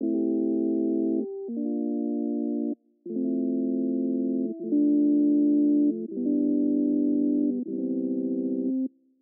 非常寒冷的合成器和弦
Tag: 140 bpm Chill Out Loops Synth Loops 4.61 MB wav Key : Unknown FL Studio